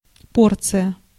Ääntäminen
IPA : /dəʊs/
IPA : /doʊs/